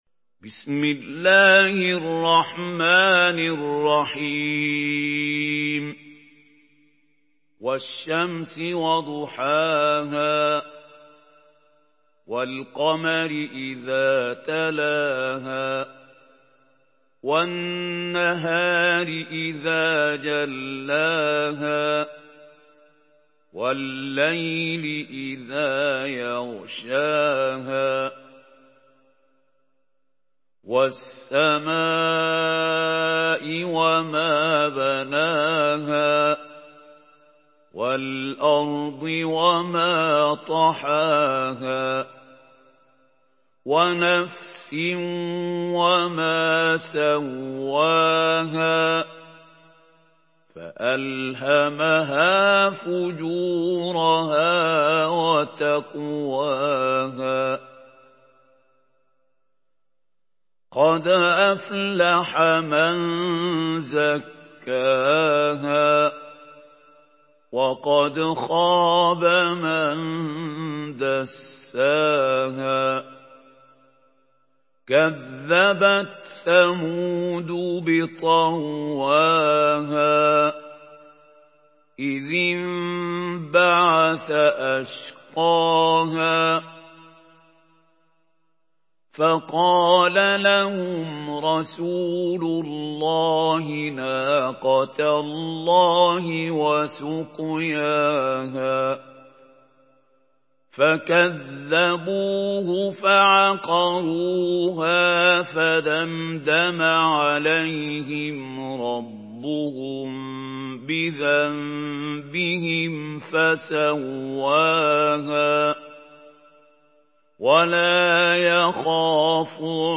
Surah Şems MP3 in the Voice of Mahmoud Khalil Al-Hussary in Hafs Narration
Surah Şems MP3 by Mahmoud Khalil Al-Hussary in Hafs An Asim narration.
Murattal